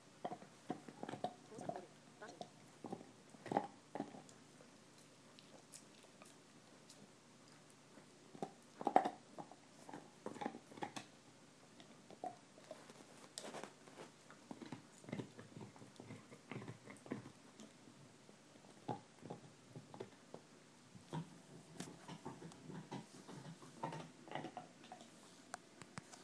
Chewing her Nylabone